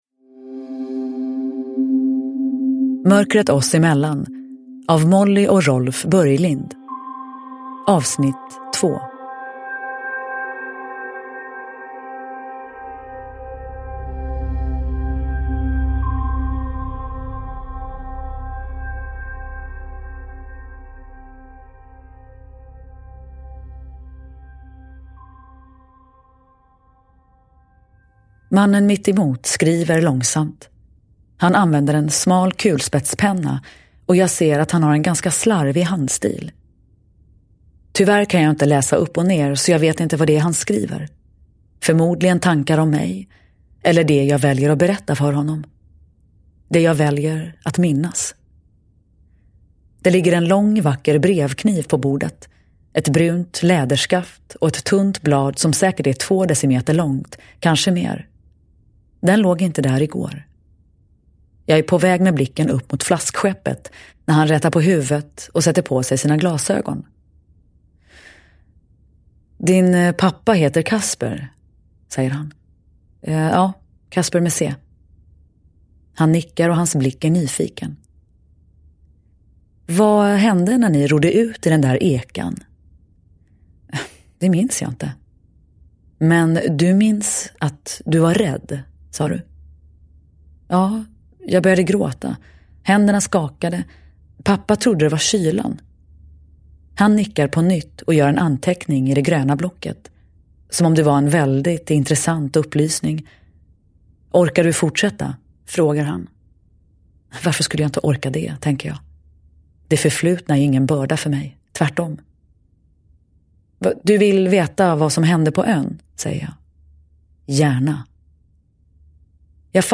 Mörkret oss emellan. 2 – Ljudbok – Laddas ner
Uppläsare: Nina Zanjani